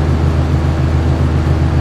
fan.wav